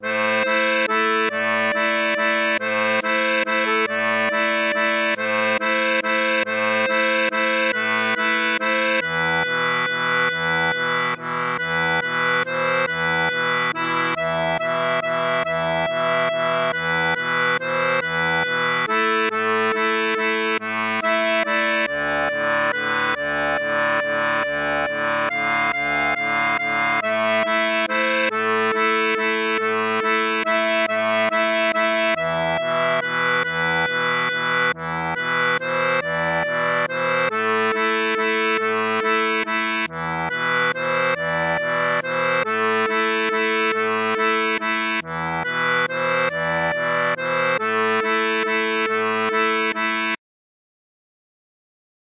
Débutant
Pop-Rock